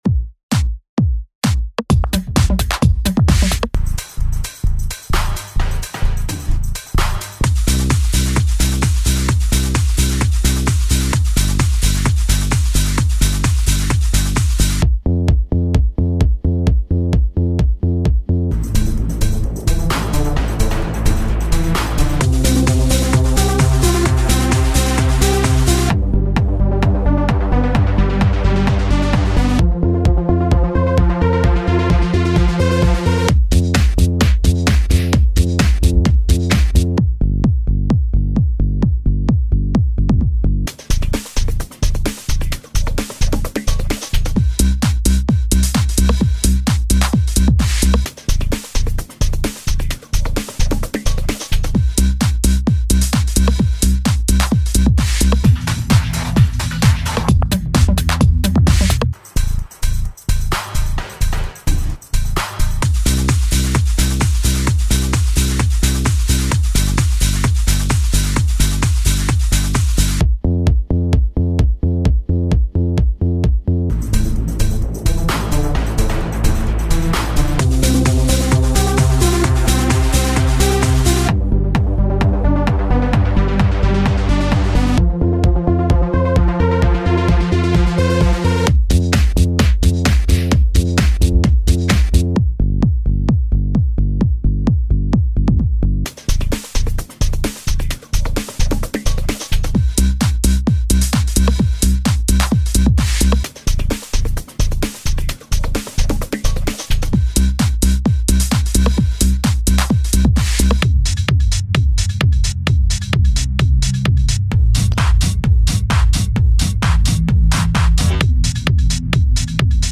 Home > Music > Ambient > Electronic > Running > Chasing